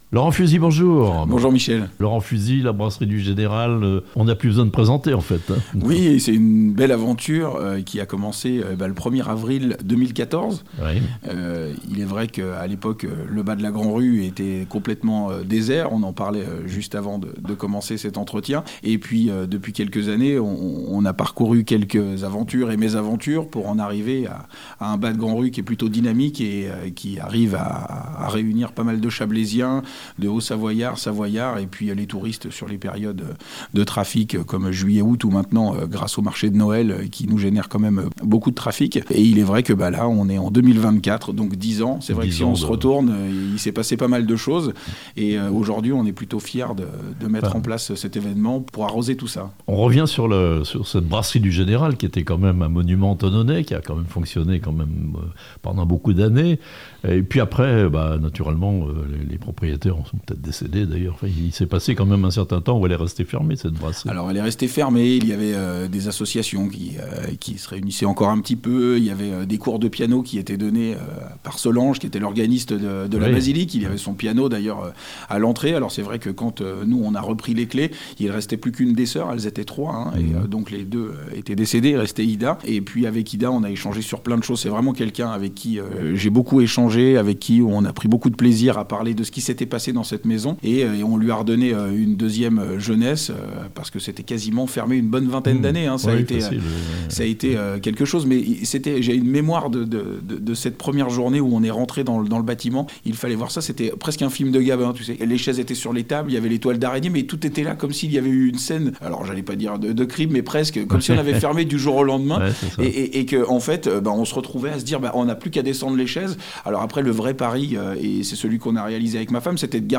Thonon : 10 ans après sa réouverture, la Brasserie du Général est devenue un restaurant en vue (interview)